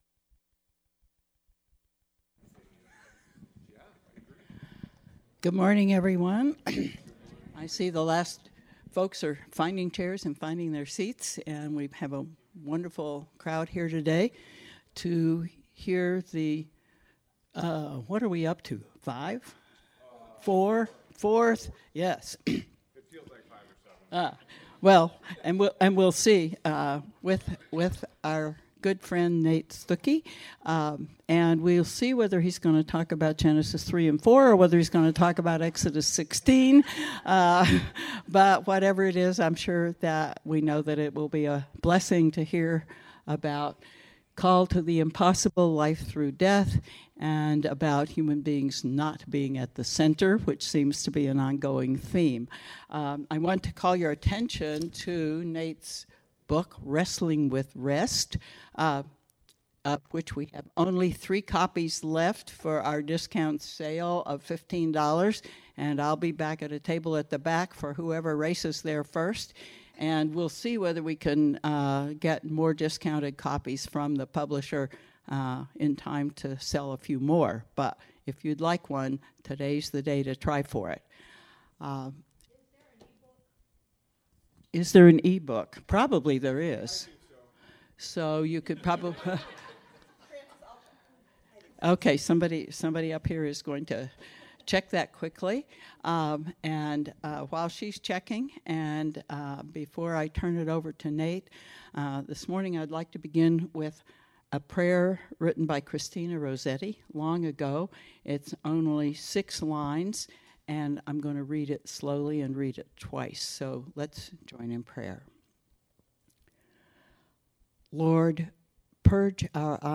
Download Flyer (pdf) Download Study Guide (pdf) Audio recordings will be posted below each class description.